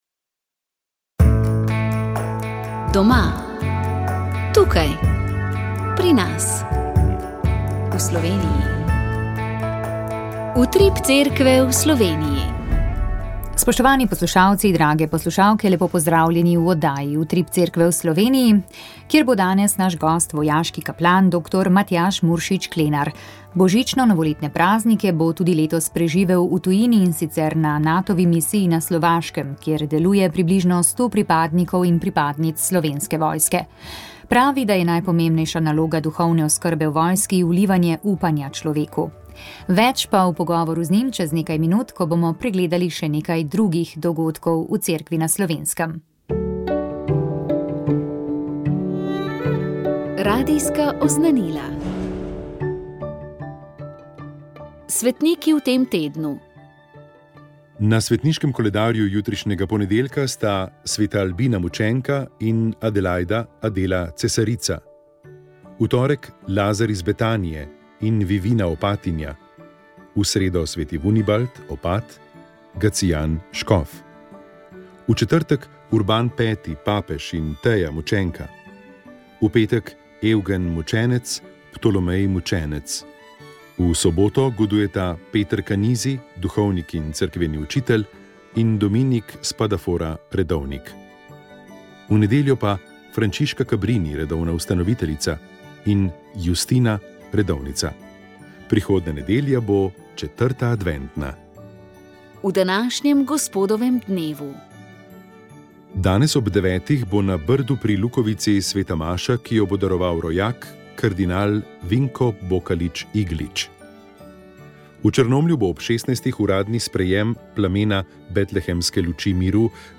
V oddaji smo povabili v Radence, na tretji Mladinski festival, in objavili pogovora s škofoma Andrejem Sajetom in Maksimilijanom Matjažem o vodni ujmi, ki je v začetku avgusta prizadela Slovenijo.